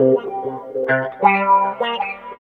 110 GTR 2 -R.wav